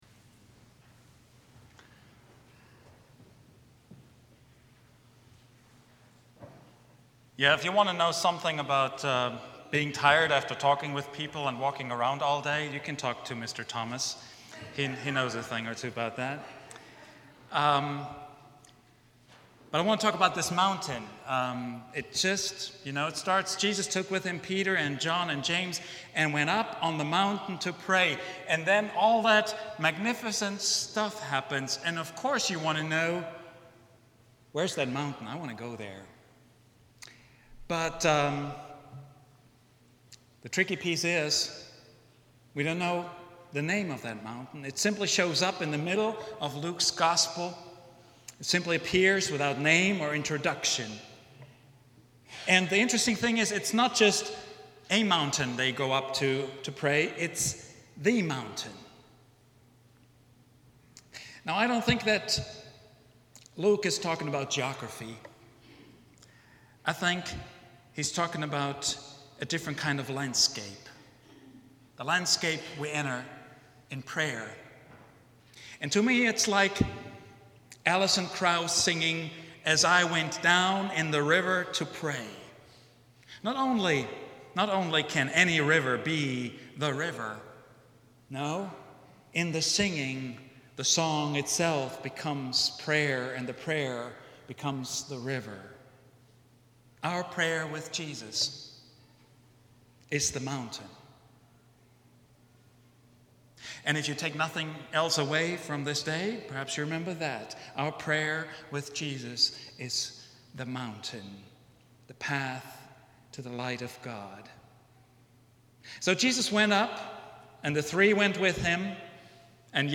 See What's There — Vine Street Christian Church